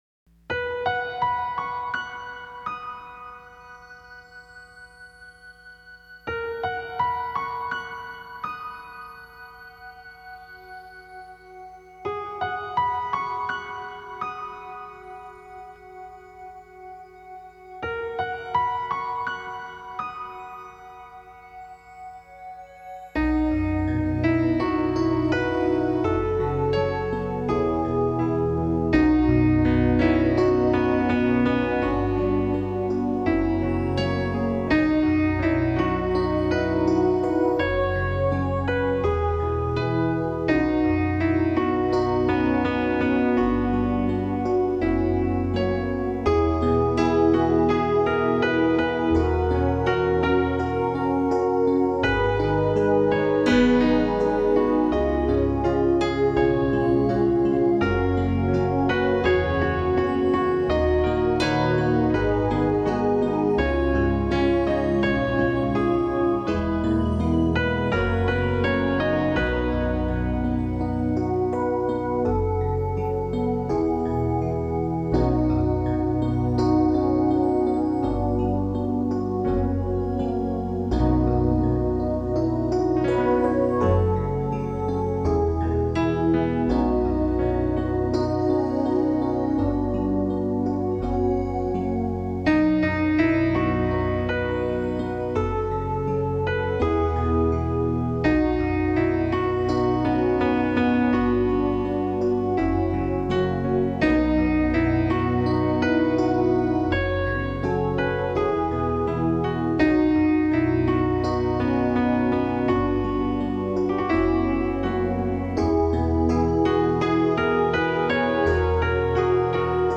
★純色無瑕的素淨琴聲
★呈現鋼琴的晶瑩質感
新世紀鋼琴極簡美學，純色無瑕的素淨琴聲
类别：精神元素